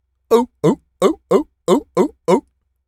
pgs/Assets/Audio/Animal_Impersonations/seal_walrus_bark_03.wav at master
seal_walrus_bark_03.wav